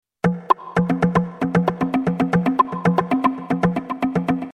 congas.mp3